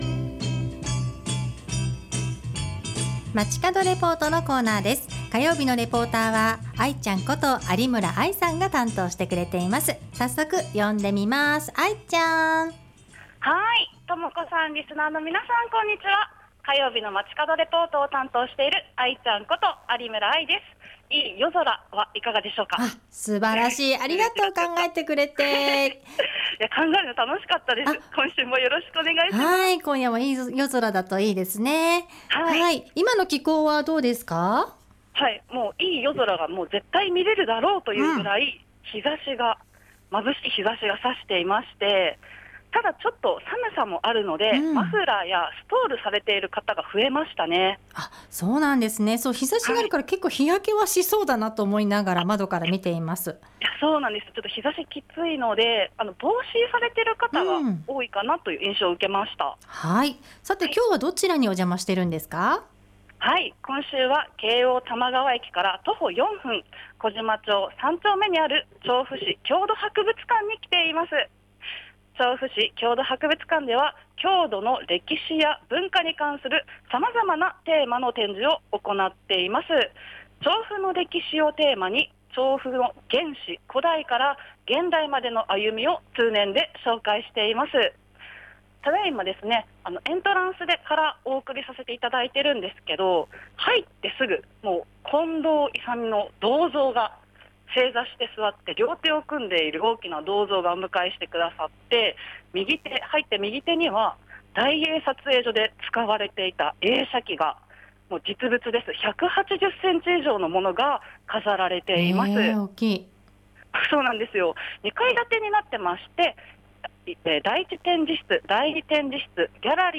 今週は調布市郷土博物館から、市制施行70周年記念企画展「写真でたどる調布のおもいで」についてお届けしました！